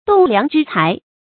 栋梁之才 dòng liáng zhī cái 成语解释 比喻能担当大事的人才 成语简拼 dlzc 常用程度 一般成语 成语例子 每个青年都应该努力向上，刻苦学习，使自己成为国家的 栋梁之才 。